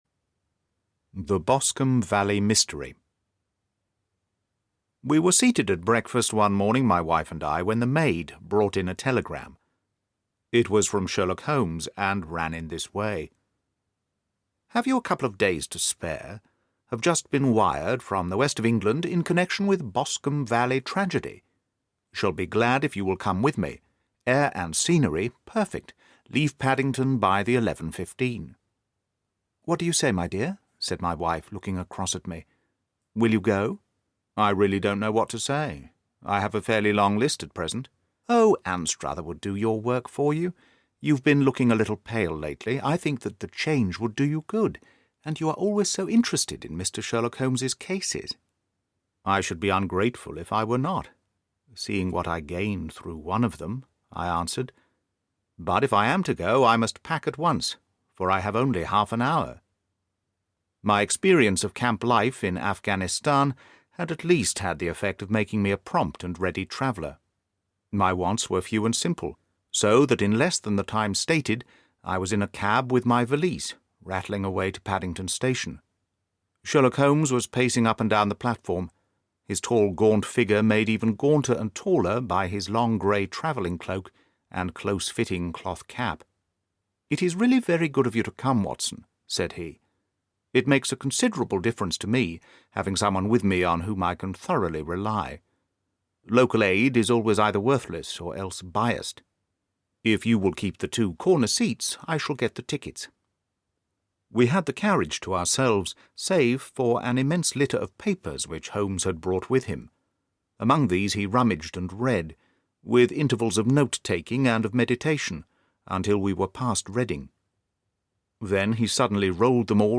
Wisteria Lodge Part One: A Sherlock Holmes Mystery (Audiobook)